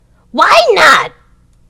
WYNAUT.mp3